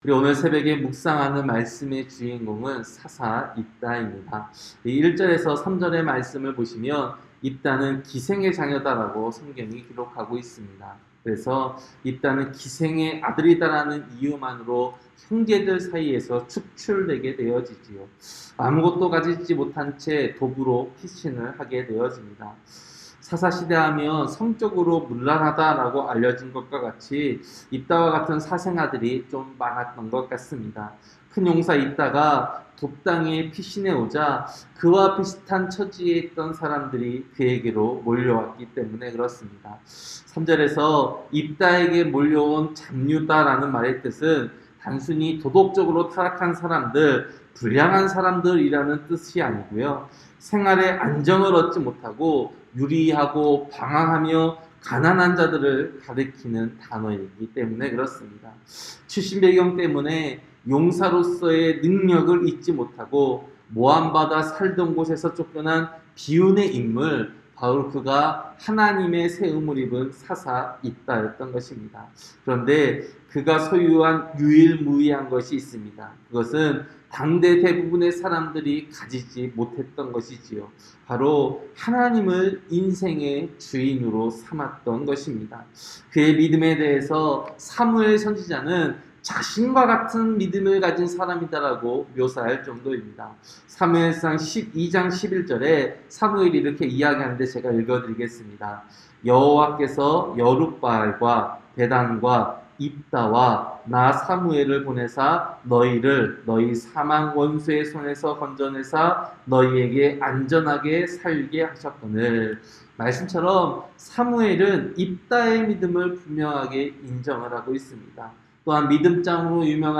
새벽설교-사사기 11장